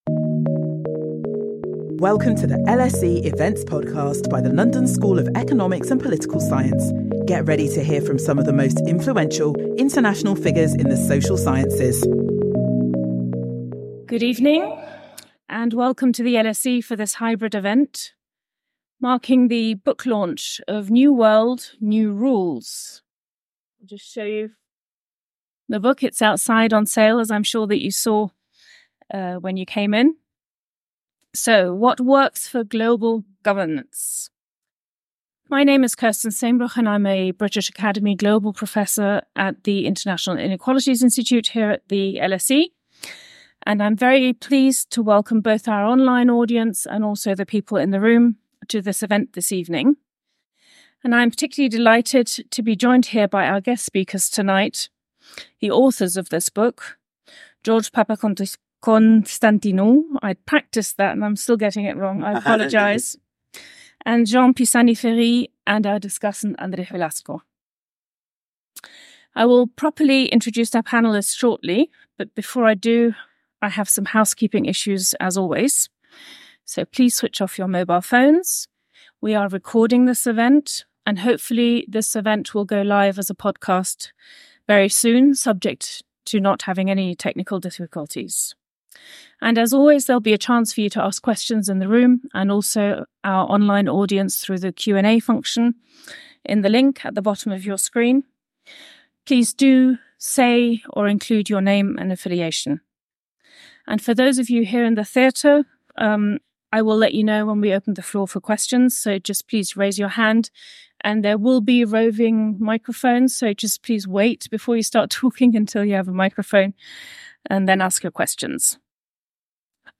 This event marks the launch of New World, New Rules by George Papaconstantinou and Jean Pisani-Ferry, in which two of European policymakers and analysts outline a new agenda for global governance.